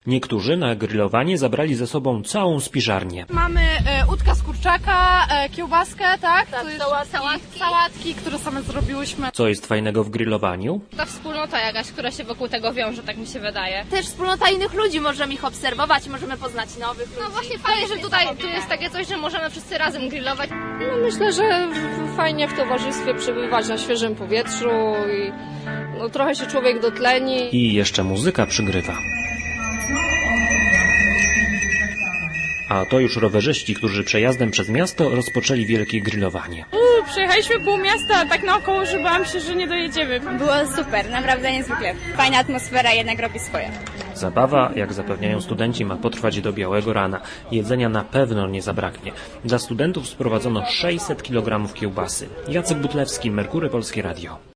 Siódme studenckie grillowanie potrwa do czwartku. Byliśmy na kampusie uniwersyteckim, by sprawdzić, czy uczestnicy dobrze się bawią